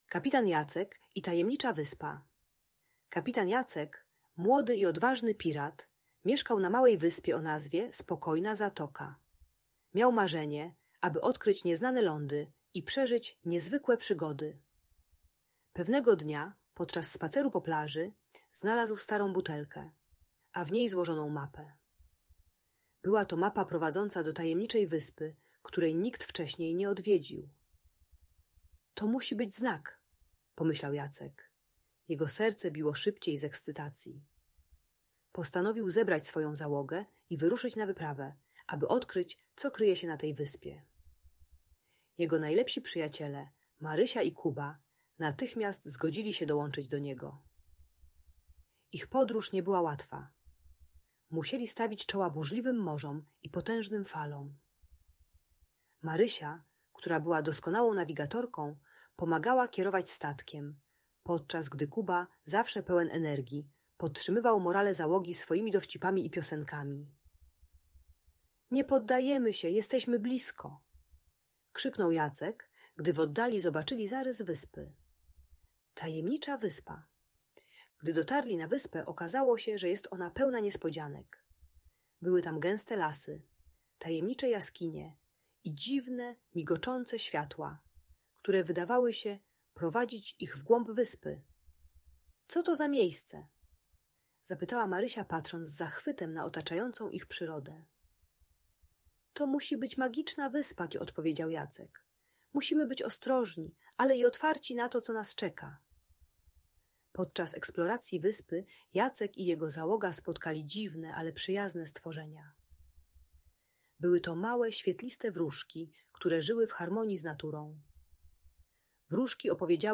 Kapitan Jacek i Tajemnicza Wyspa - Niepokojące zachowania | Audiobajka